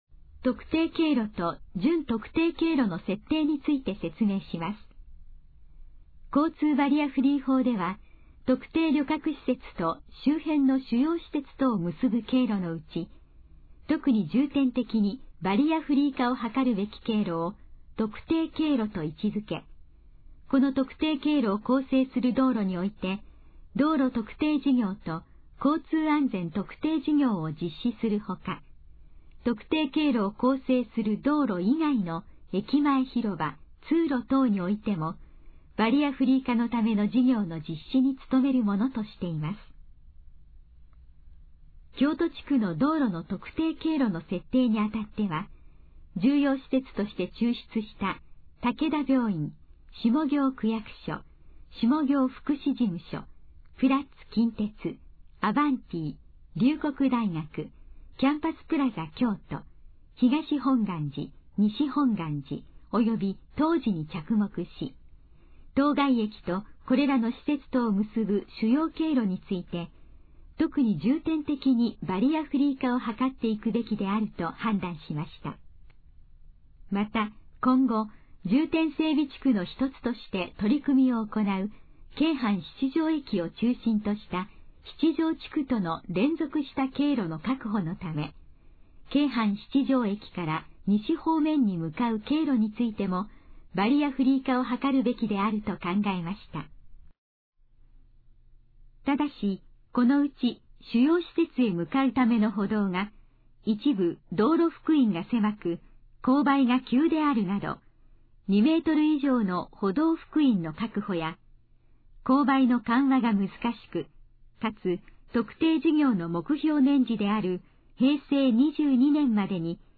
以下の項目の要約を音声で読み上げます。
ナレーション再生 約306KB